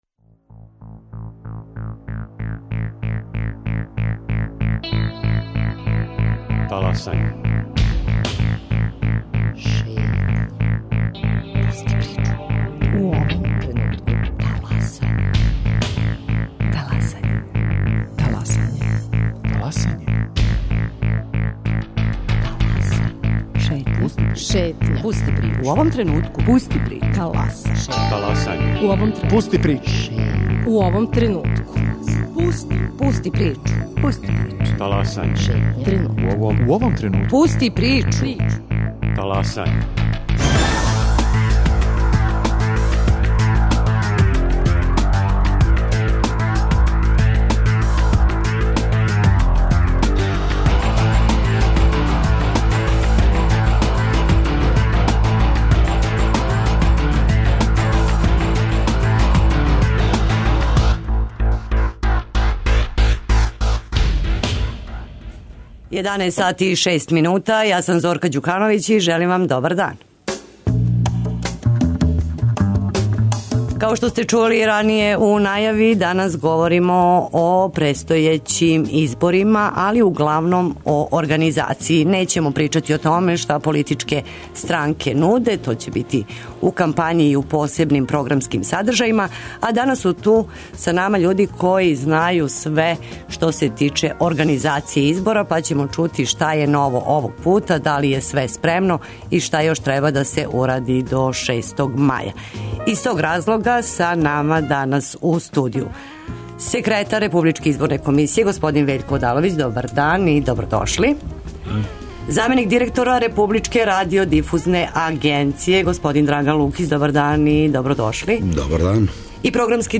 Гости "Таласања" су Вељко Одаловић, секретар Републичке изборне комисије